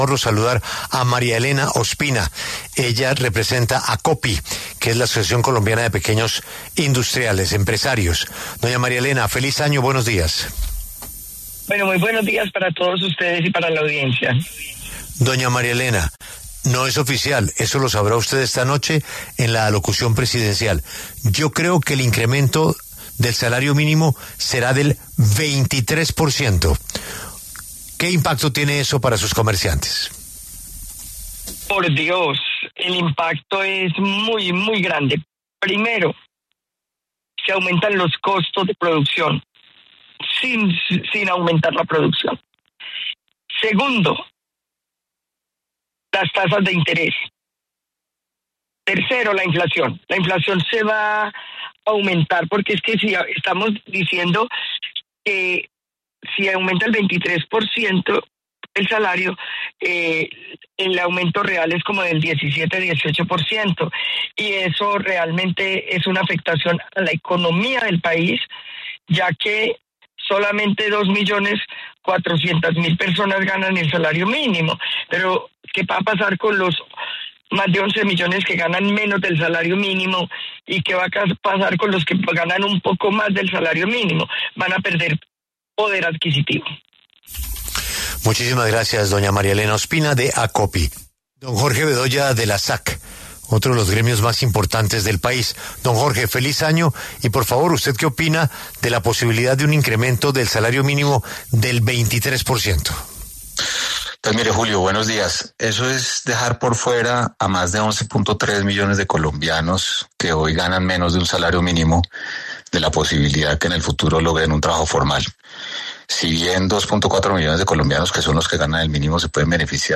En los micrófonos de La W, con Julio Sánchez Cristo, hablaron diferentes gremios empresariales, quienes se refirieron a un hipotético caso en el que el salario mínimo para el 2026 aumente más del 20%